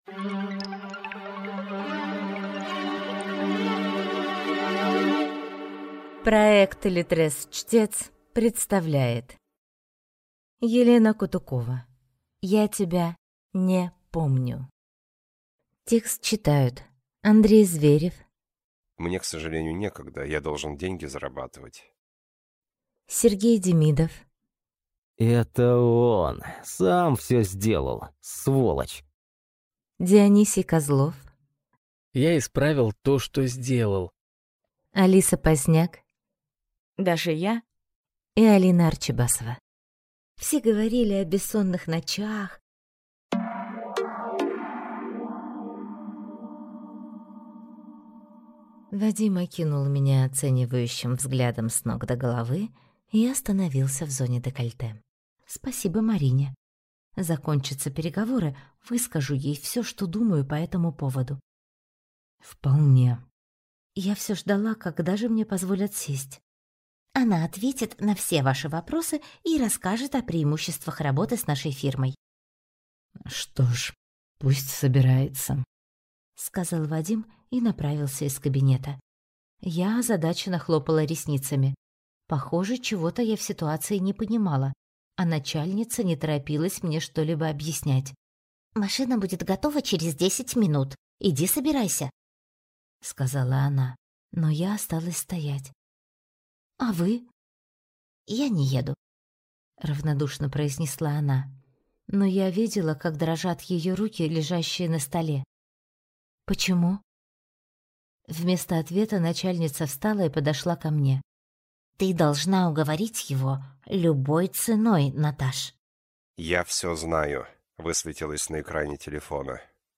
Аудиокнига Я тебя (не) помню | Библиотека аудиокниг